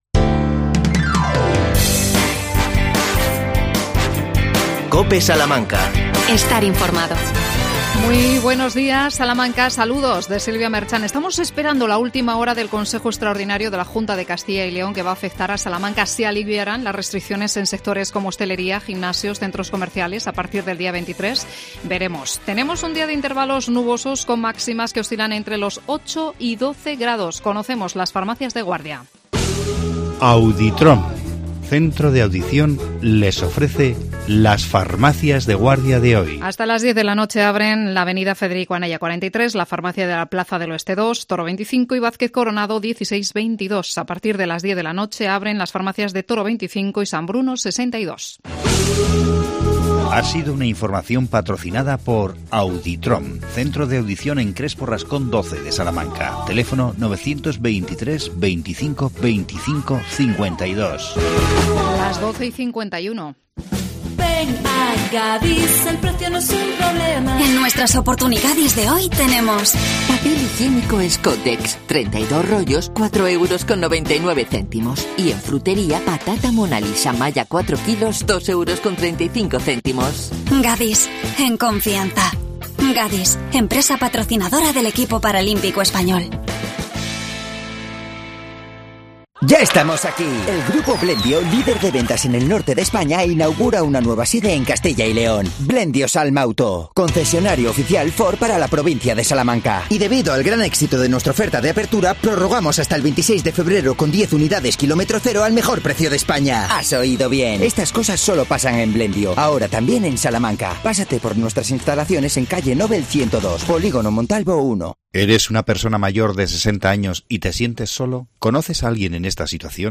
Entrevista a Miguel A. Luengo, alcalde de La Alberca.